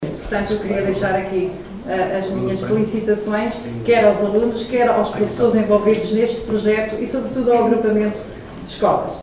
A vereadora da Educação na Câmara de Monção manifestou publicamente, em reunião do Executivo Municipal, o seu orgulho por mais um prémio conquistado pelo Agrupamento de Escolas. Desta vez foi a turma E do 8º ano, que conquistou recentemente o primeiro prémio – 500 euros – no concurso escolar Segredos do Alto Minho, na modalidade Criação Literária, com o poema O Último Dragão.